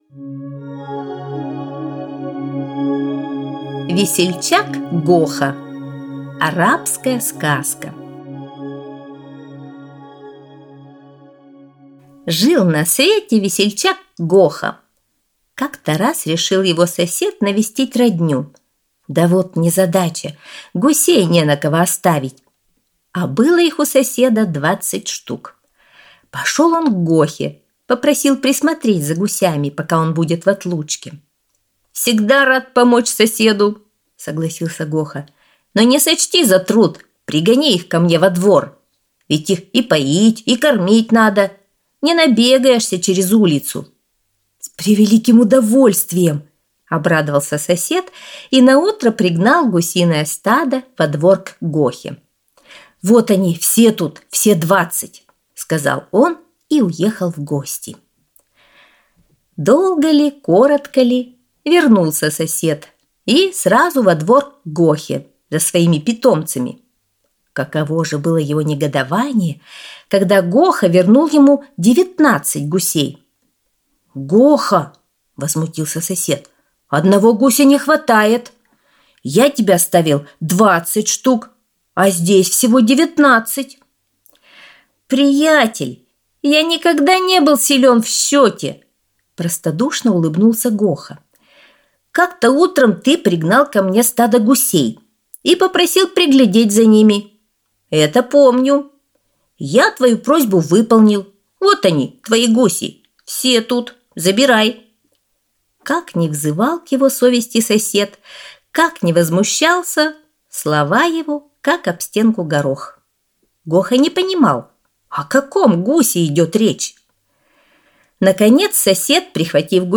Весельчак Гоха – арабская аудиосказка